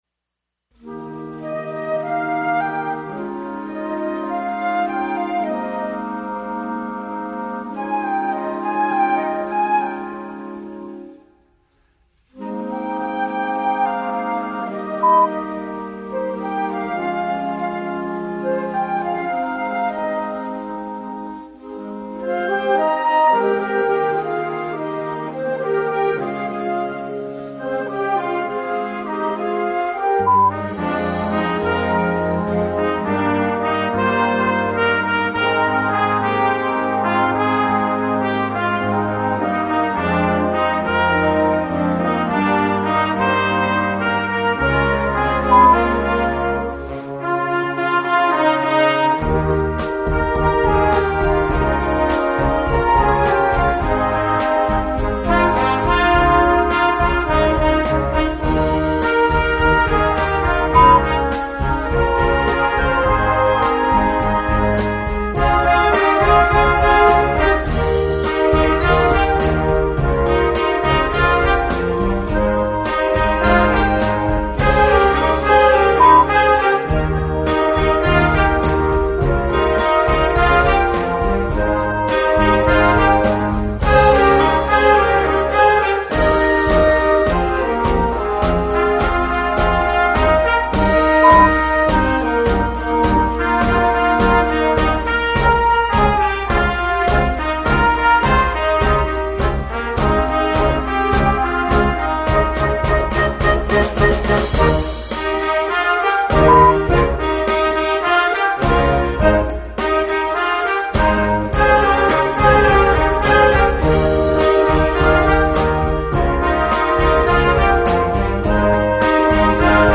Schwierigkeit: 1,5
Besetzung: Blasorchester
easy arrangement of the popular rock ballad